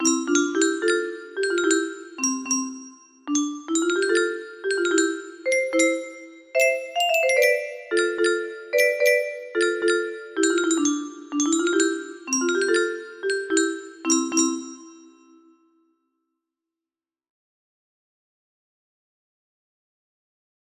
reverie in a minor music box melody